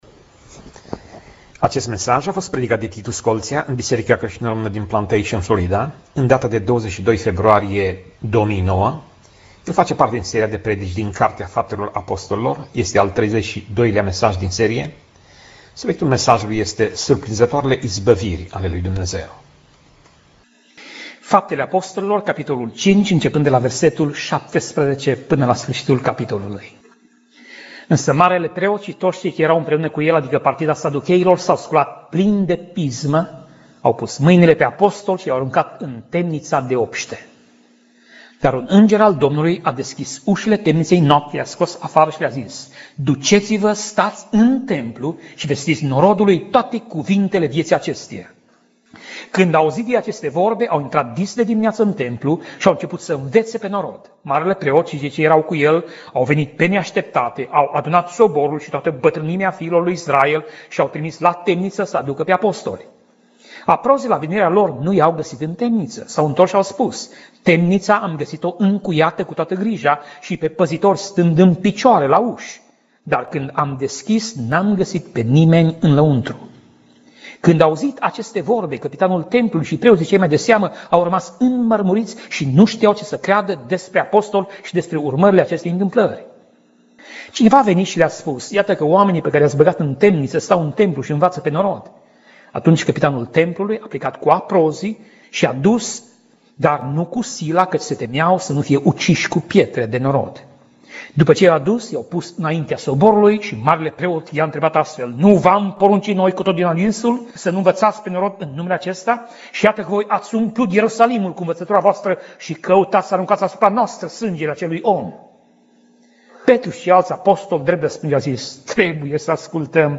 Pasaj Biblie: Faptele Apostolilor 5:12 - Faptele Apostolilor 5:42 Tip Mesaj: Predica